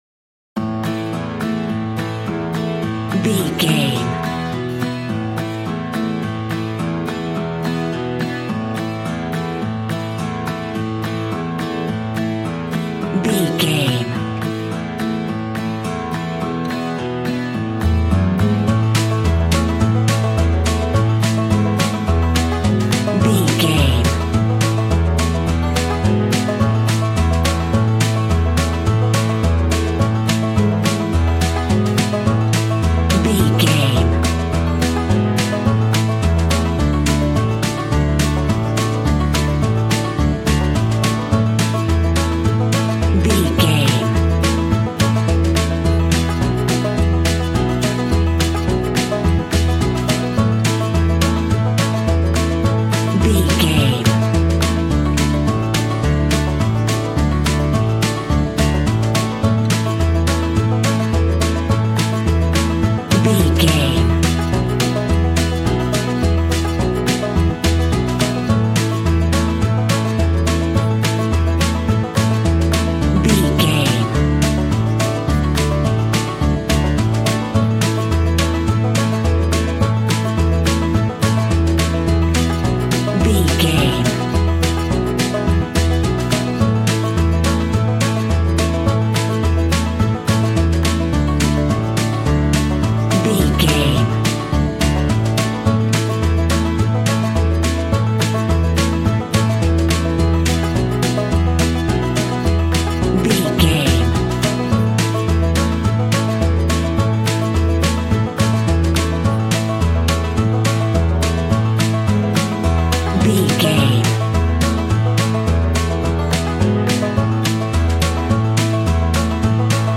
Ionian/Major
Fast
fun
bouncy
positive
double bass
drums
acoustic guitar